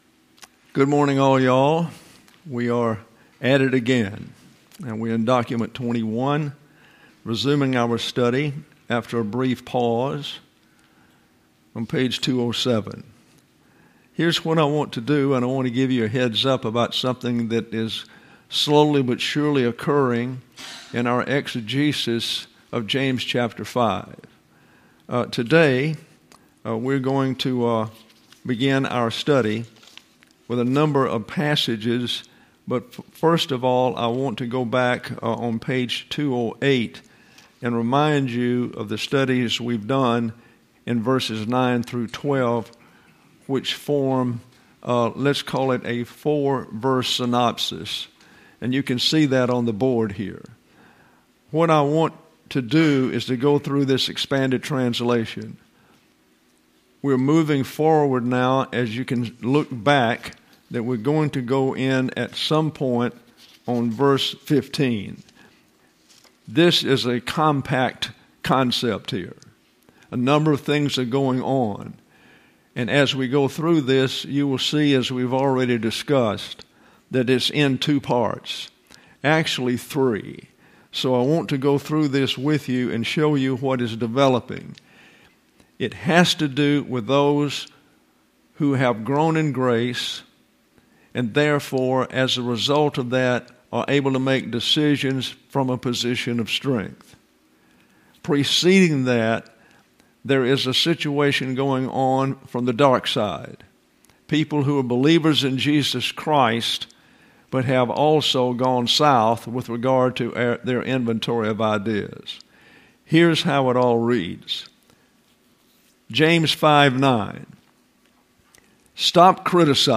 James Chapter Five: Lesson 75: Analysis of Verse 12: Expanded Translation of Verses 9-12; Preview of The Paragraph in Verses 13-18: The Principle of Redeeming Time by Means of Prayer: Prayer in Four Categories: Prayer for a Nation in Reversionism; The Knocking at the Door is Warning Discipline: Stages of Reversionism: Three Types of Discipline for the Individual and the Nation;